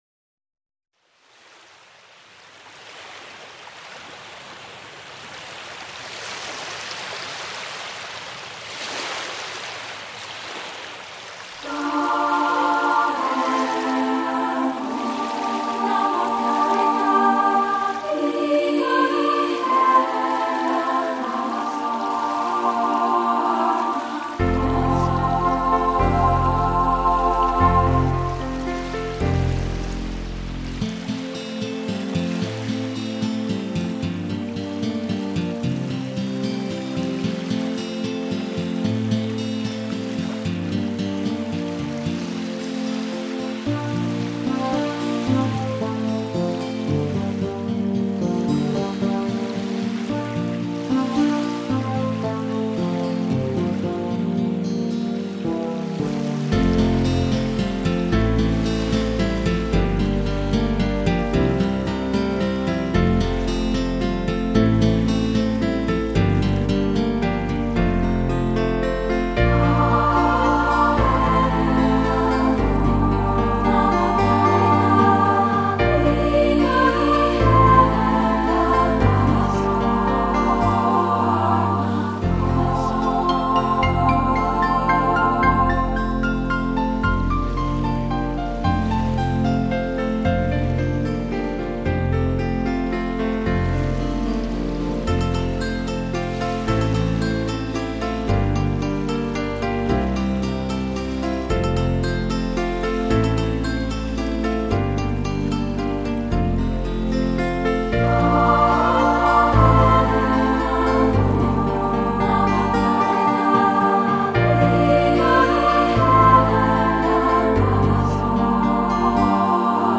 自然天籁邂逅美声音符在微光中低吟